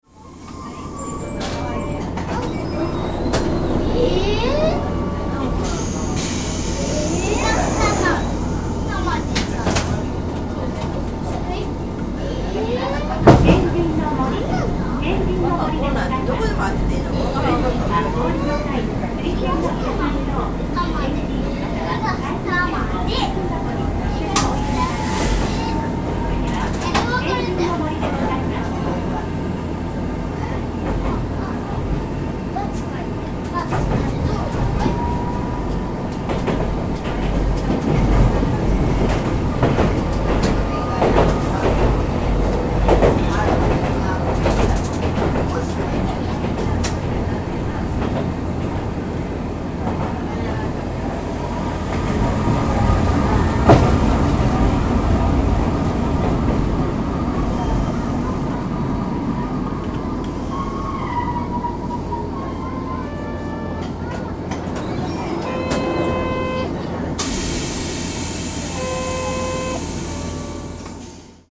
住吉を出て恵美須町へ向かう阪堺電車の車内。まるで女性専用車で賑やか。